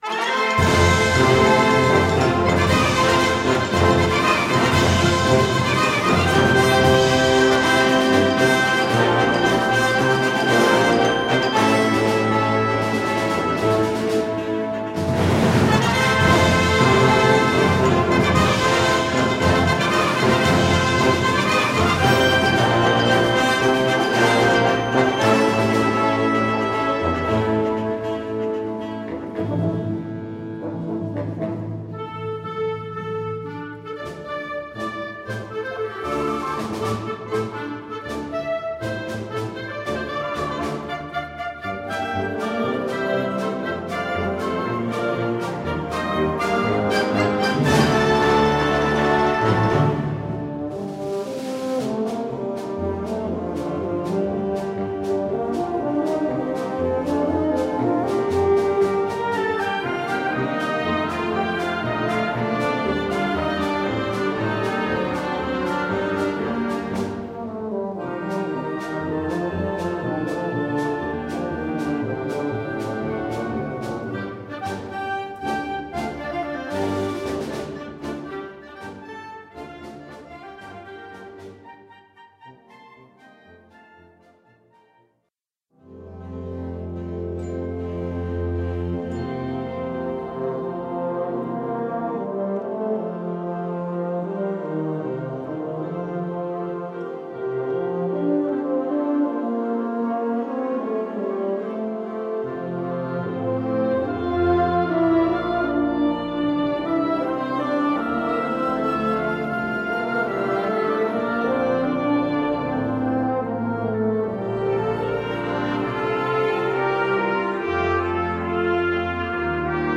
Categoría Banda sinfónica/brass band
Subcategoría Obertura (obra original)
Instrumentación/orquestación Ha (banda de música)